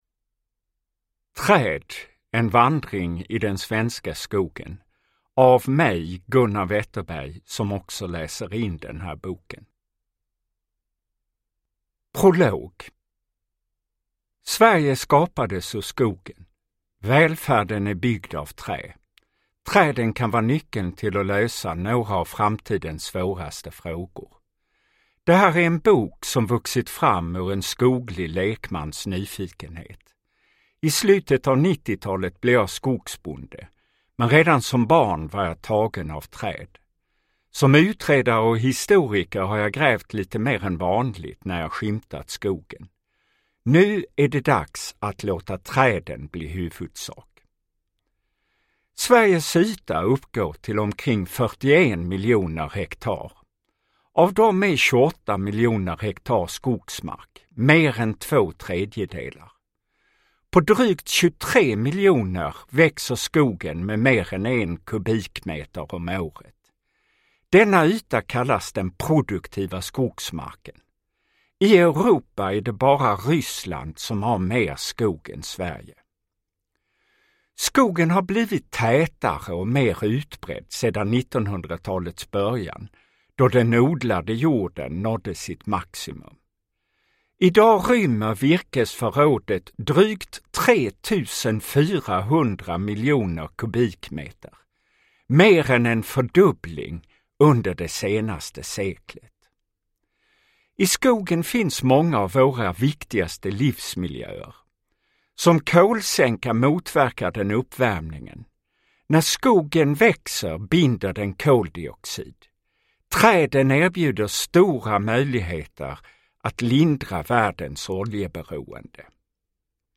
Uppläsare: Gunnar Wetterberg
Ljudbok